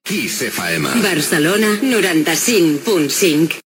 Indicatiu i freqüència de l'emissora a Barcelona
Jingles Radio